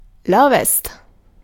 Ääntäminen
IPA : /wɛst/